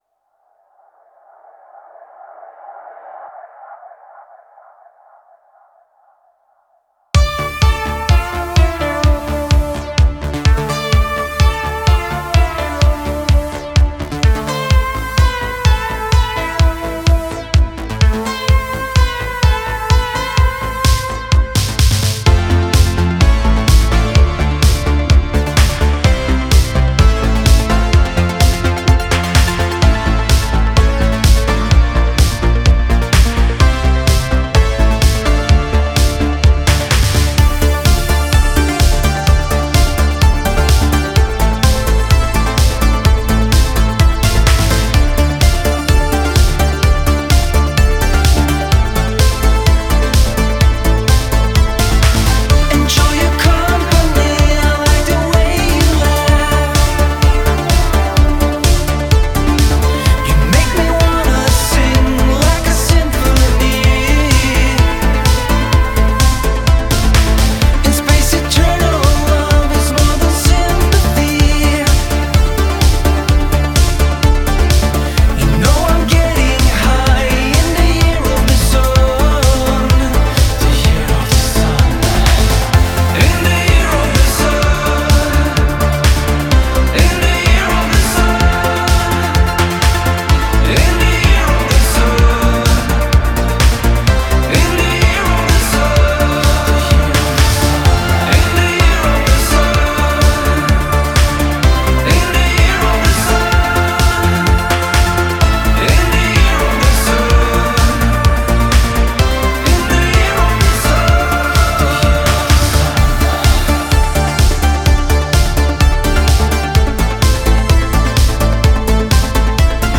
По-моему, классно, так просто и мелодично))